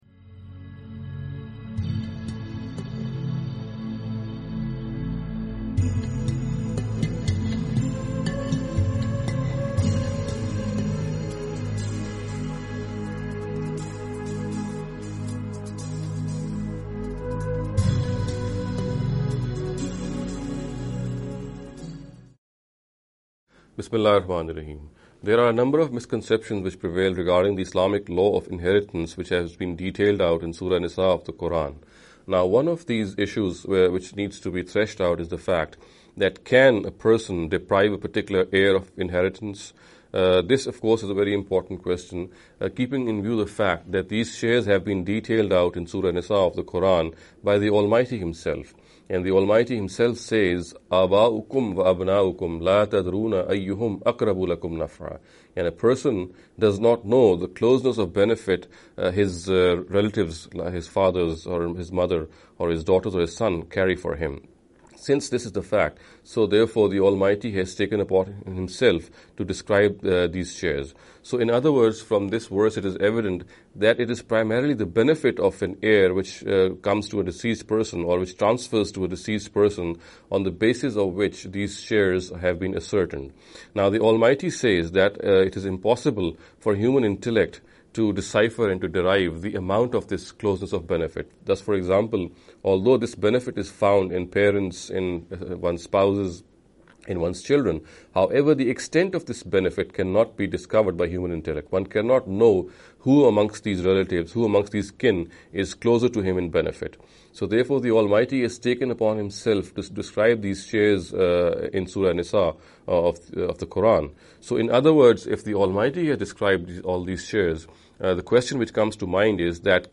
This lecture series will deal with some misconception regarding the Economic Directives of Islam.